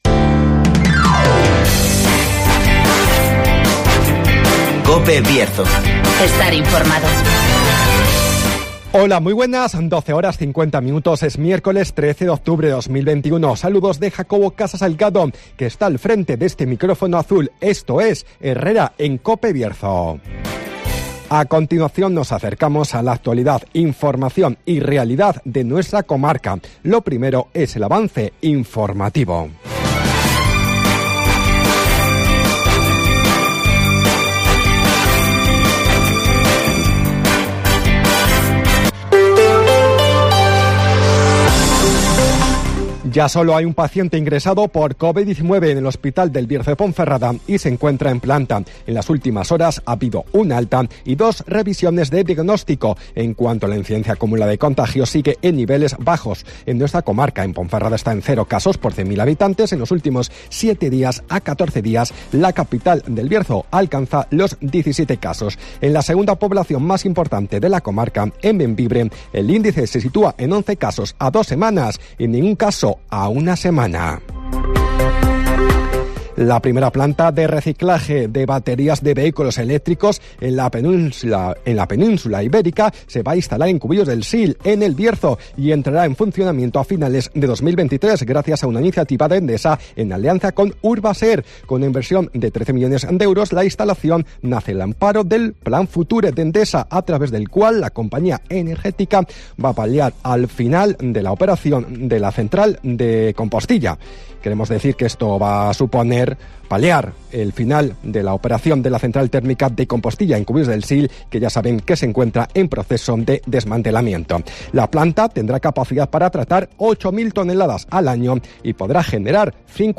Avance informativo, El Tiempo y Agenda.